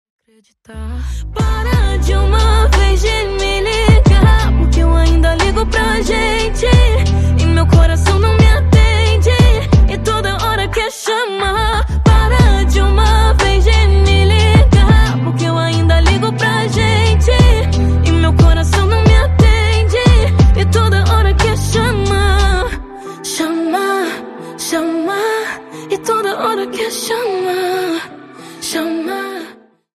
Brasileira